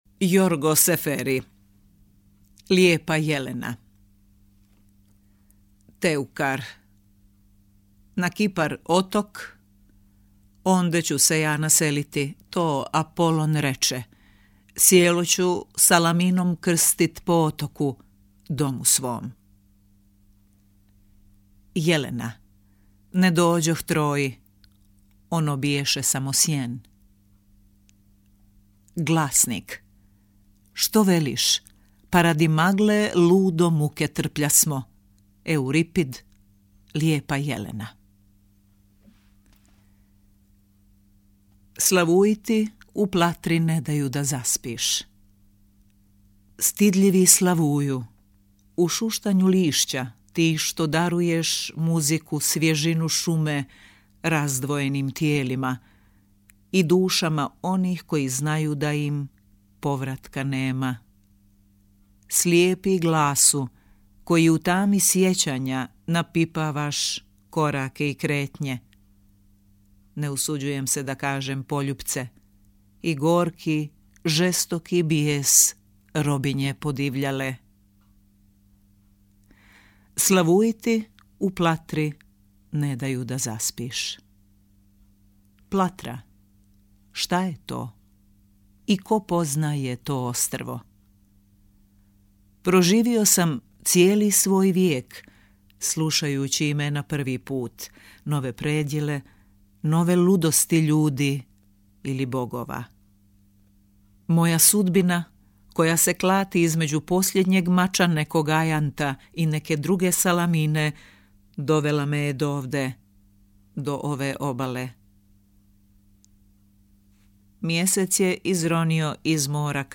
Poeziju čitaju
- prevedene tekstove čita